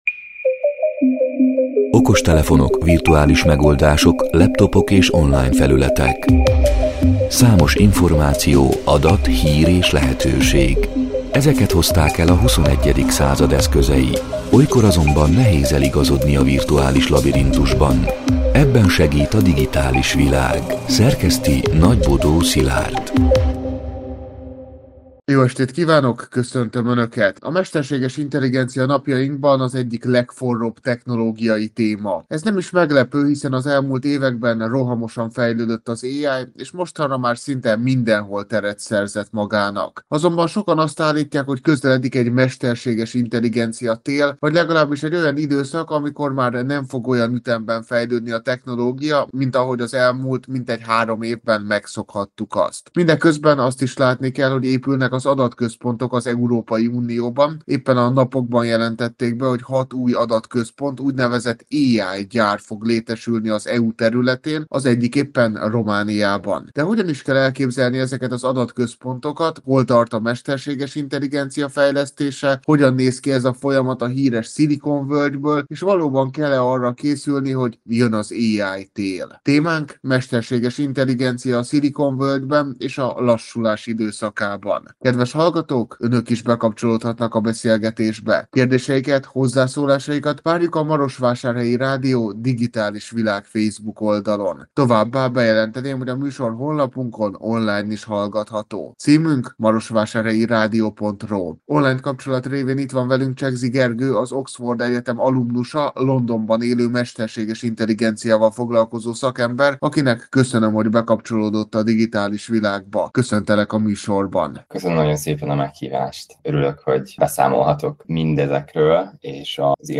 A Marosvásárhelyi Rádió Digitális Világ (elhangzott: 2025. október 21-én, kedden este nyolc órától) c. műsorának hanganyaga: